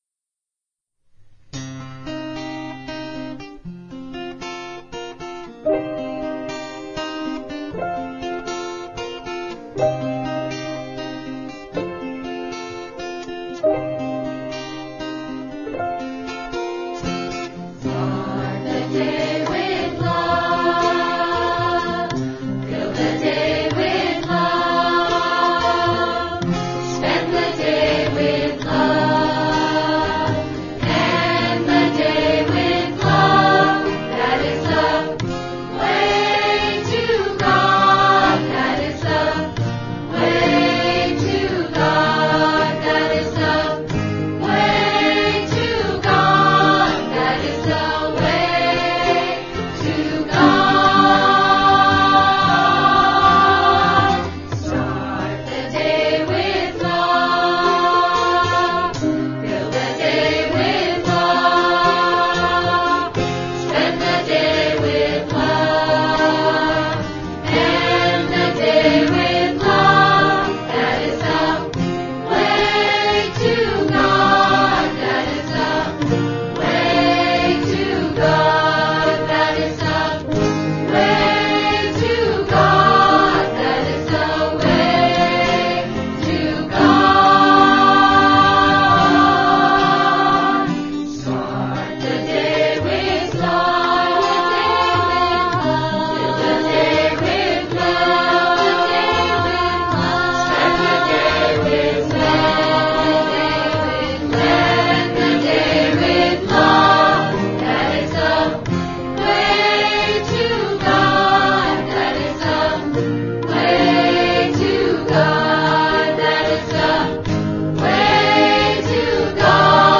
1. Devotional Songs
8 Beat / Keherwa / Adi
Lowest Note: p / G (lower octave)
Highest Note: N1 / B♭
This song is best sung in unison.